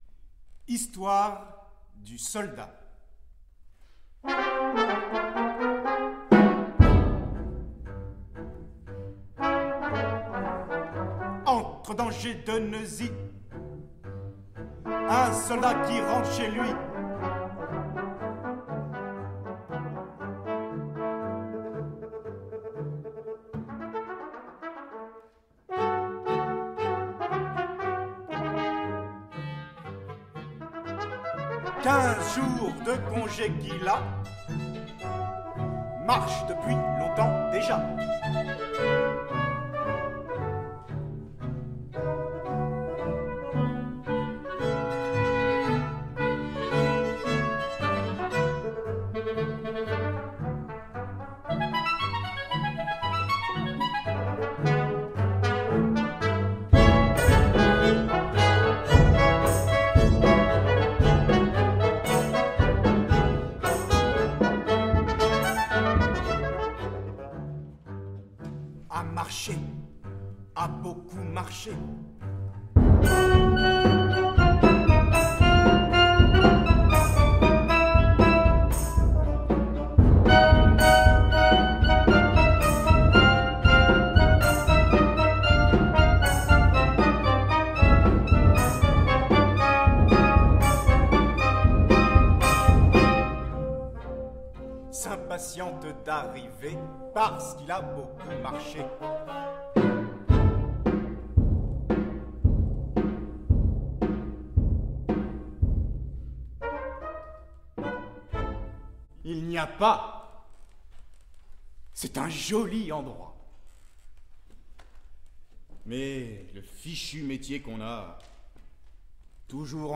L'œuvre se termine par le triomphe du démon dans une marche sarcastique.